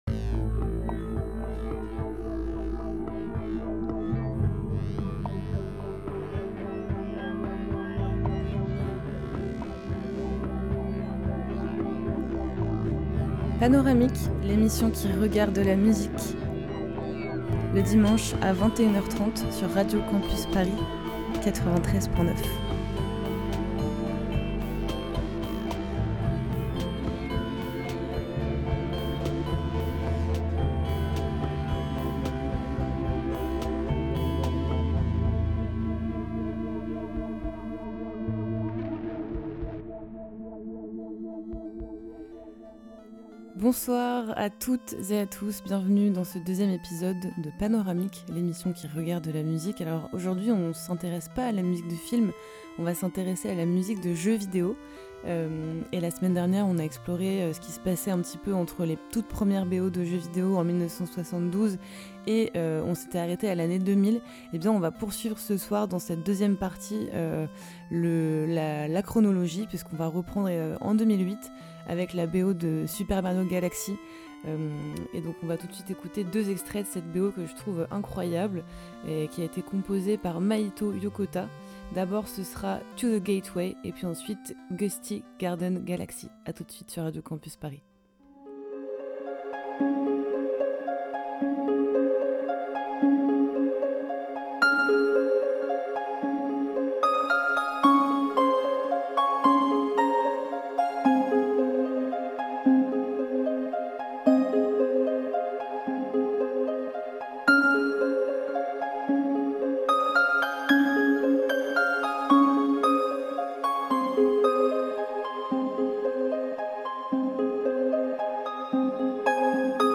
magnifique B.O. pleine de synthé qui scintille
Type Mix